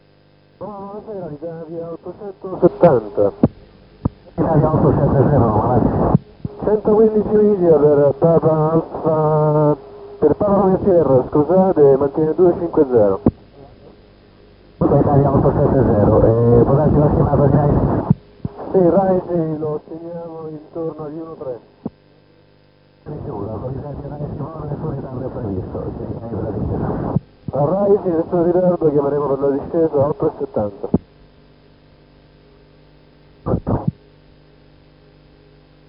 Ultime comunicazioni radio tra DC9 e Roma Ciampino